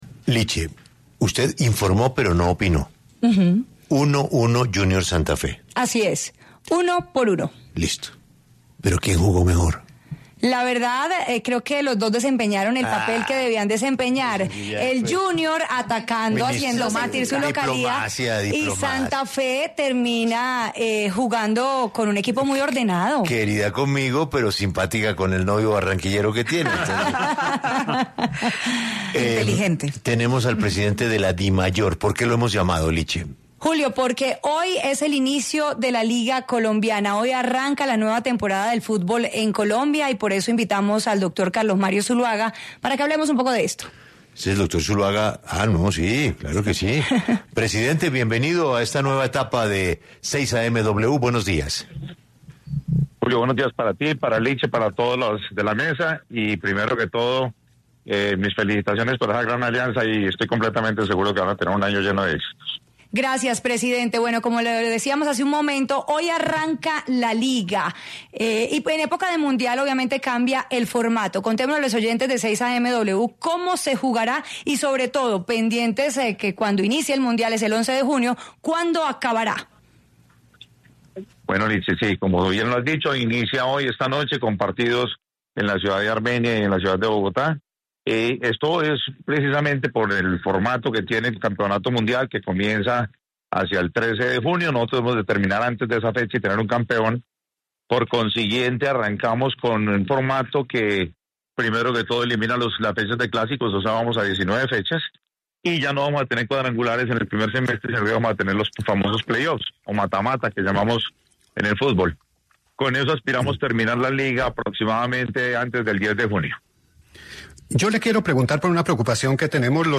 habló en los micrófonos de 6AM W, con Julio Sánchez Cristo